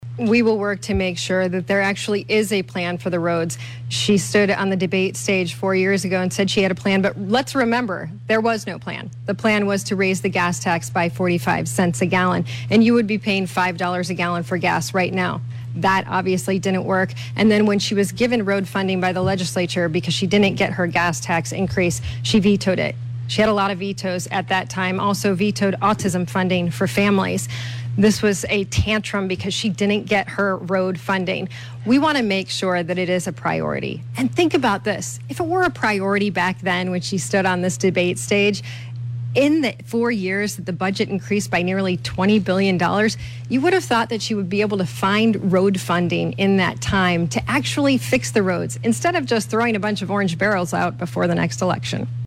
The debate brought the two contenders together for hour-long event at Oakland University.